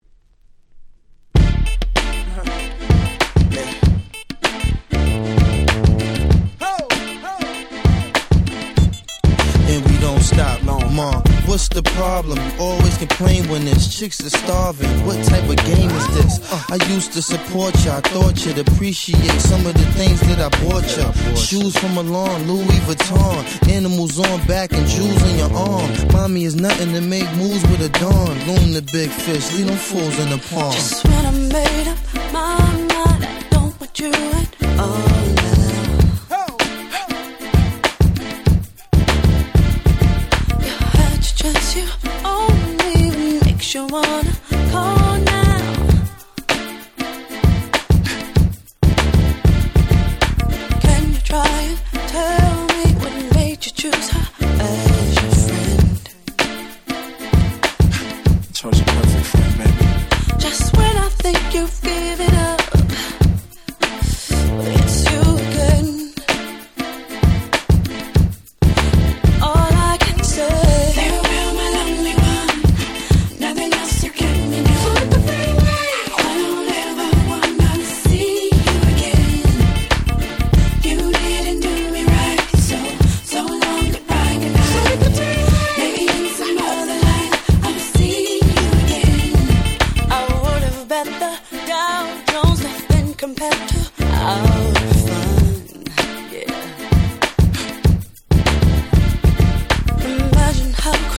02' Smash Hit R&B Album !!